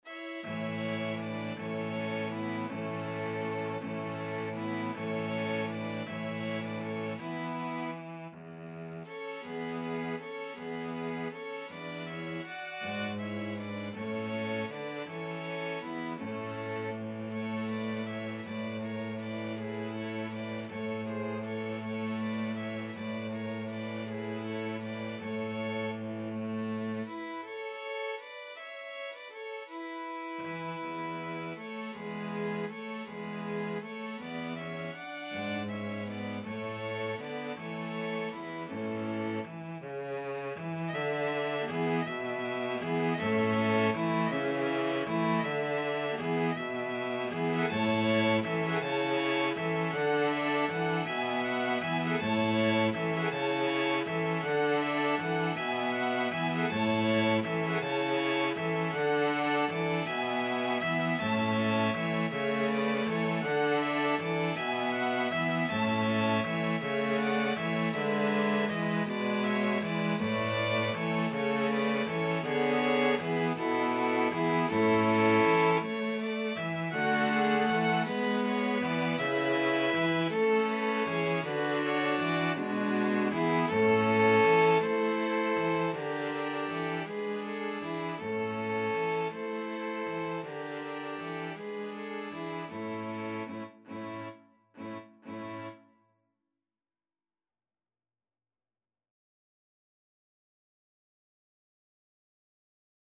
Violin 1Violin 2ViolaCello
Sicilian carol
~ = 80 Allegro moderato (View more music marked Allegro)
6/8 (View more 6/8 Music)